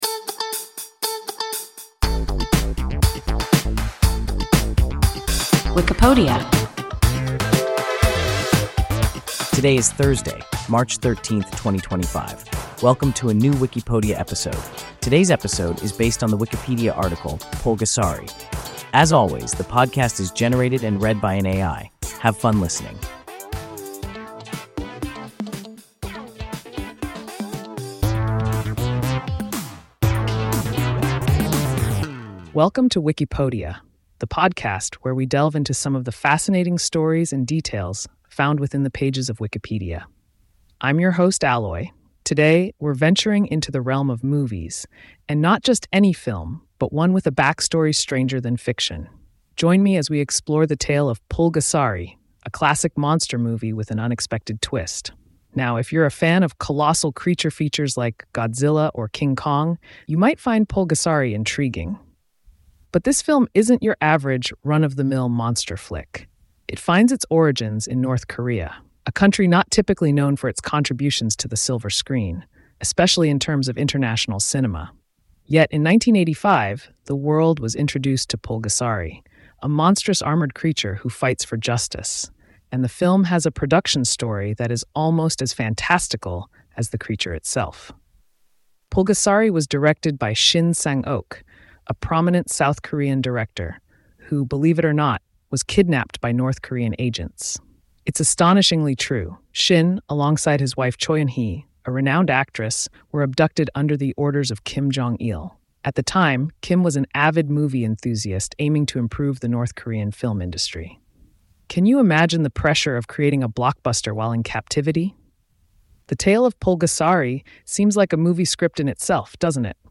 Pulgasari – WIKIPODIA – ein KI Podcast